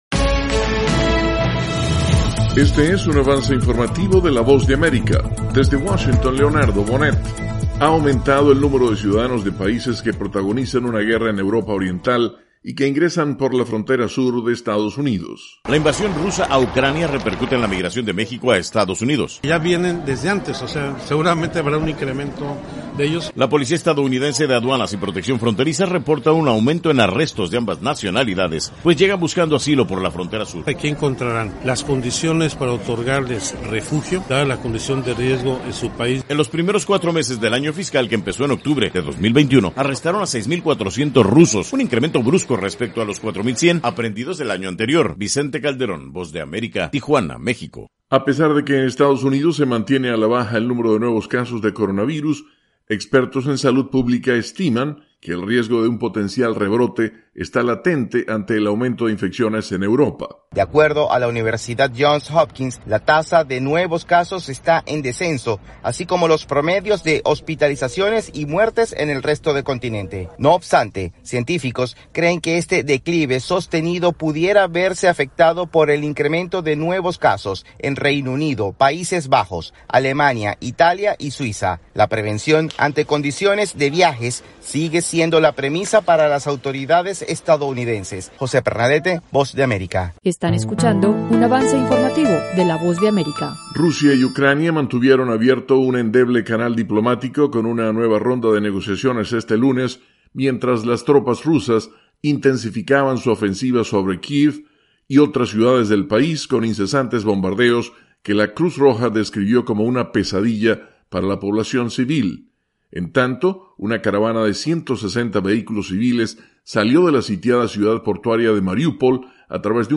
Avance Informativo - 7:00 PM